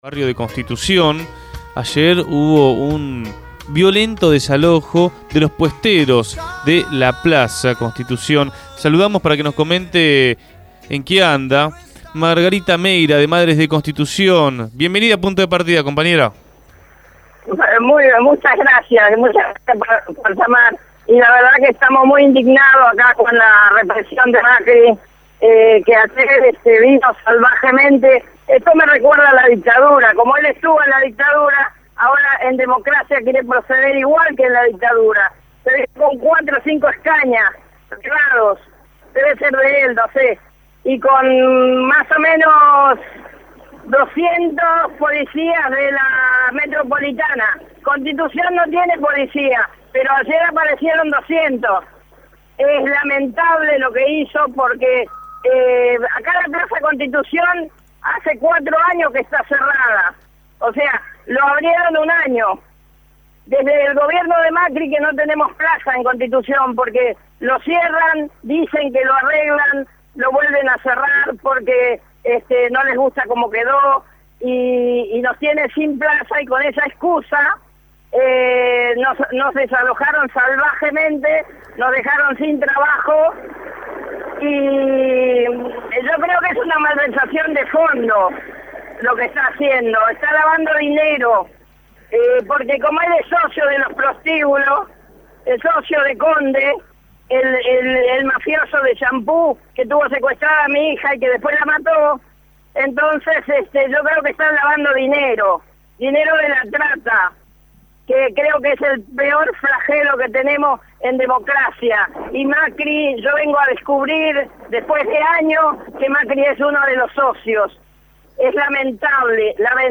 En diálogo con